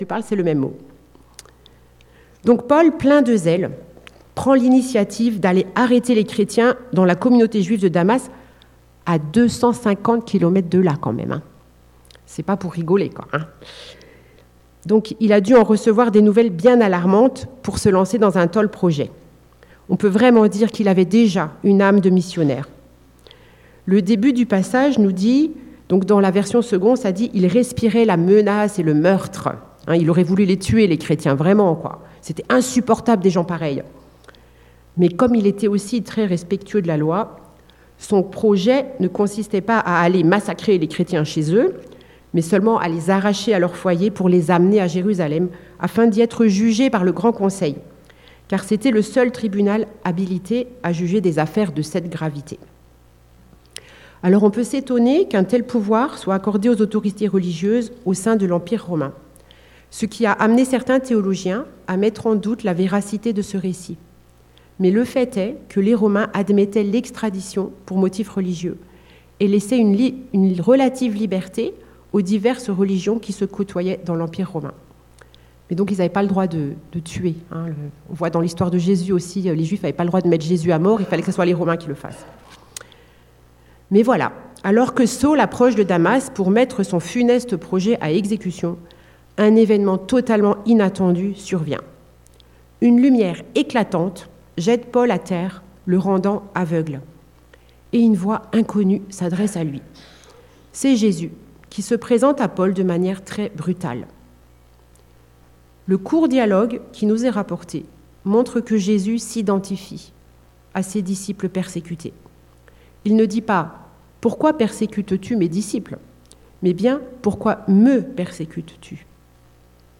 Ecoutez les différents messages de l'église évangélique de Bouxwiller … continue reading 336 jaksoa # Religion # Christianisme # EEBouxwiller # Culte # Chrétien # Croire En Dieu